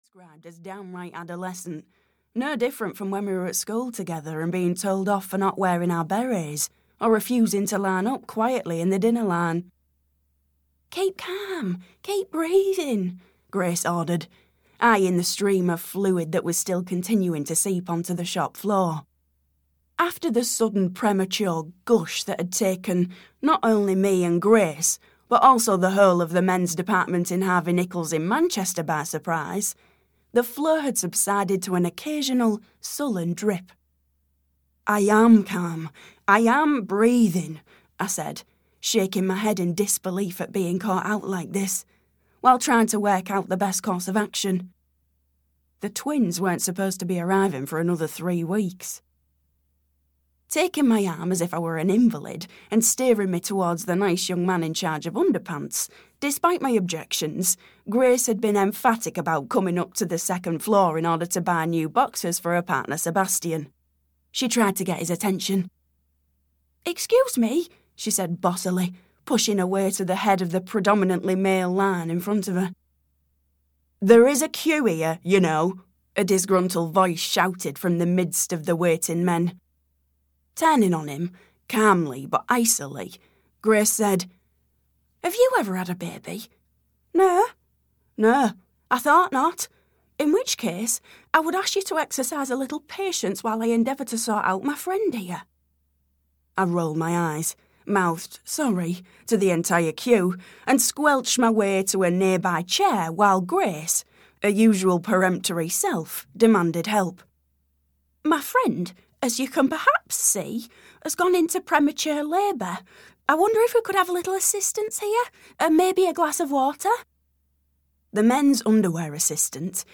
The One Saving Grace (EN) audiokniha
Ukázka z knihy